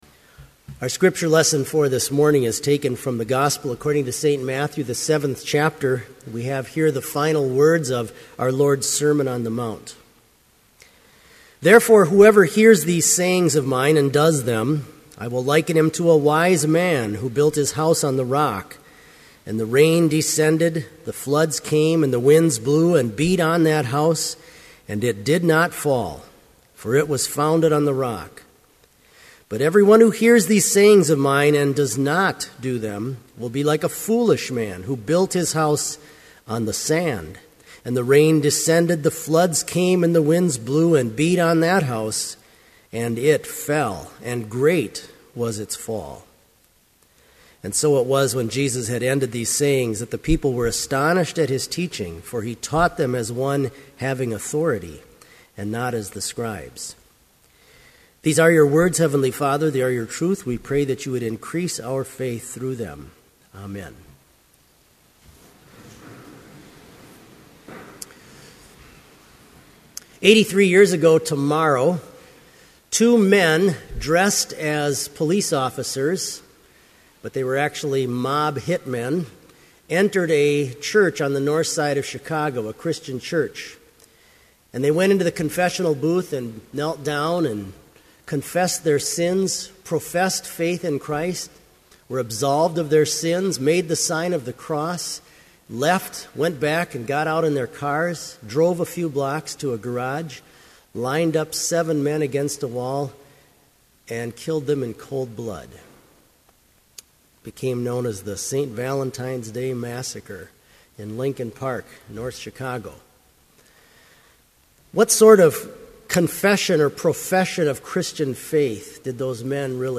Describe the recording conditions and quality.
This Chapel Service was held in Trinity Chapel at Bethany Lutheran College on Monday, February 13, 2012, at 10 a.m. Page and hymn numbers are from the Evangelical Lutheran Hymnary.